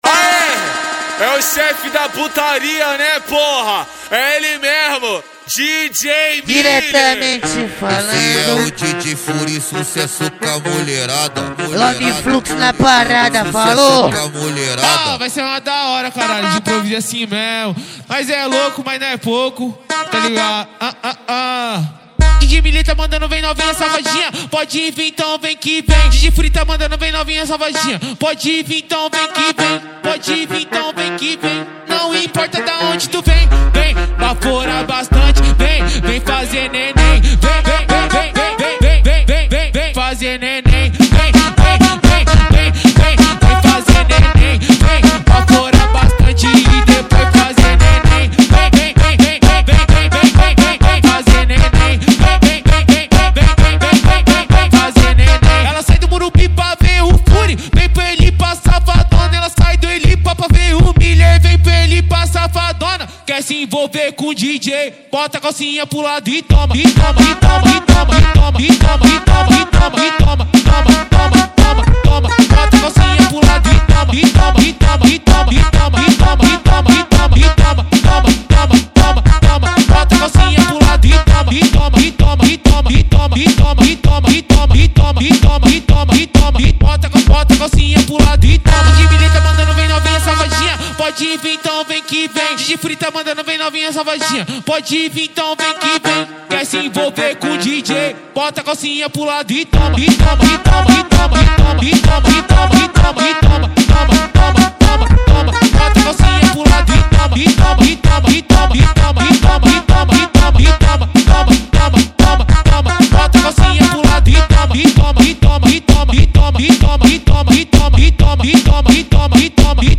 2024-12-23 00:49:30 Gênero: MPB Views